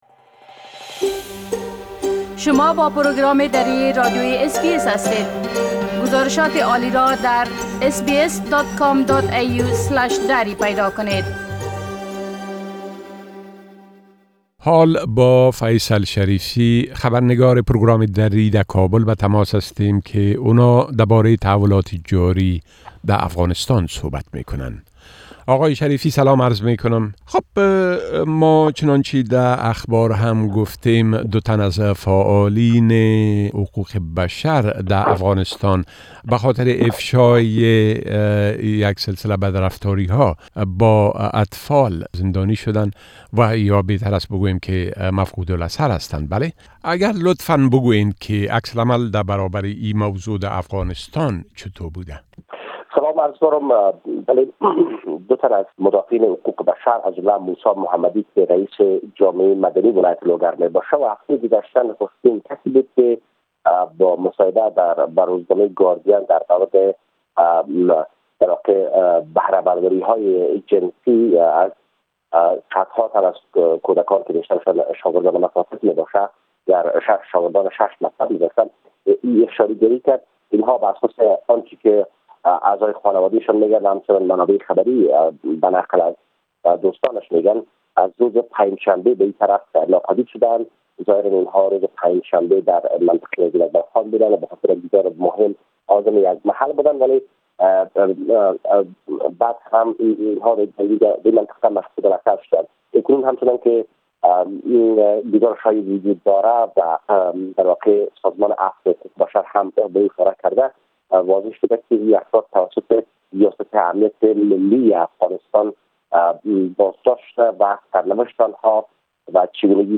گرازش كامل خبرنگار ما در كابل دربارۀ تحولات تازه در ارتباط به بازشمارى آراى انتخابات رياست جمهورى٬ حوادث امنيتى و رويداد هاى مهم ديگر در افغانستان را در اينجا شنيده ميتوانيد.